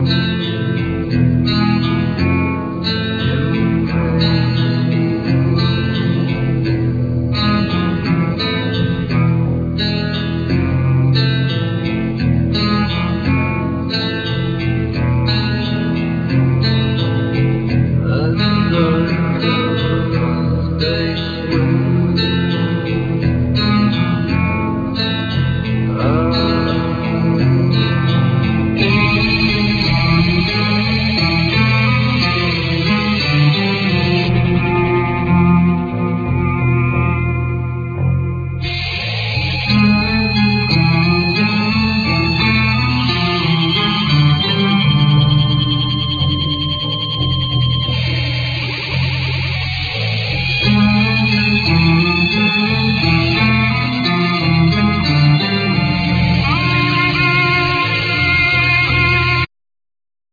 Vocal,Violin,Guitar
Cello
Guitar,Bass guitar,Tambourine
Drum,Bass guitar